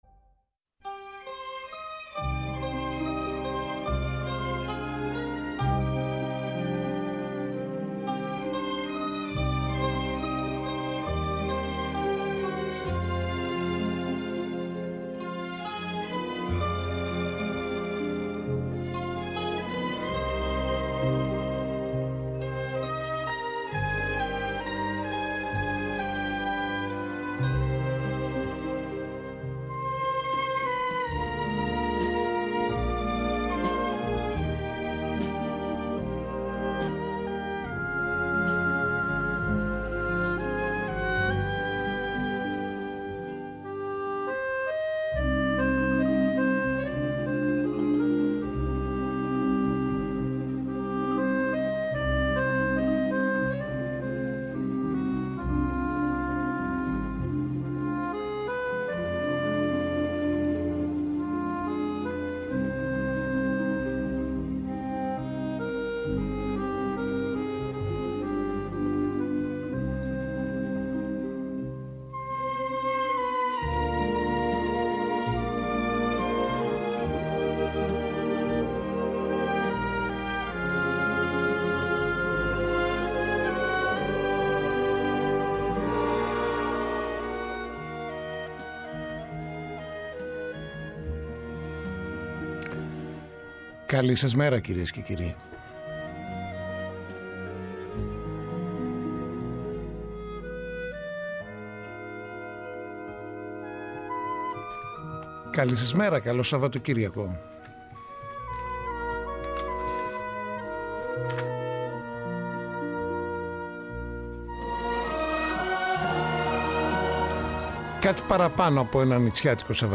καλεσμένους, ρεπορτάζ και σχόλια.